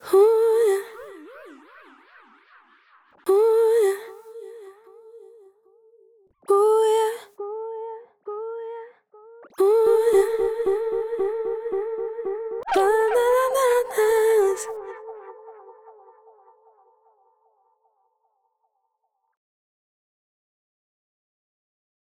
Meine persönlichen Highlights beim Timeless 2 sind die abgefahrenen Filtereffekte, die sich hiermit fabrizieren lassen.
Ohne Sättigung der Filter klingt Timeless 2 sauber und klar. Gibst Du den Filtern aber etwas mehr Drive, kannst Du den Grunge eines echten Bandverzögerungseffekts emulieren.
fabfilter_timeless2_demo.mp3